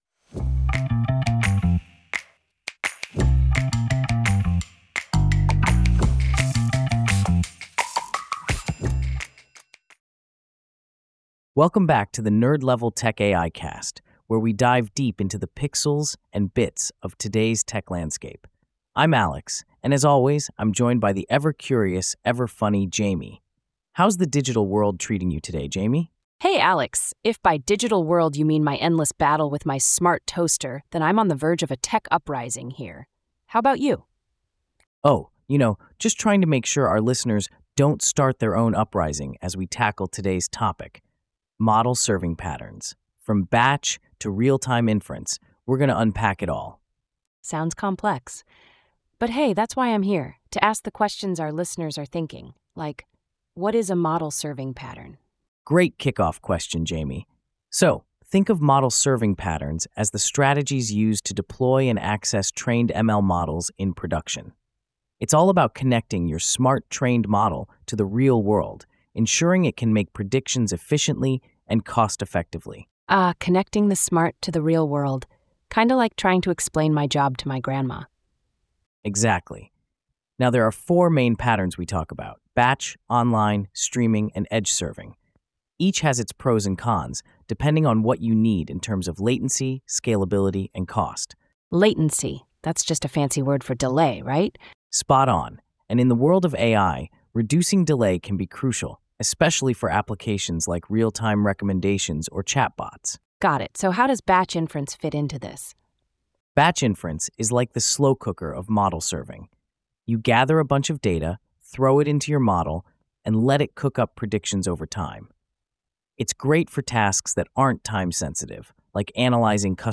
AI-generated discussion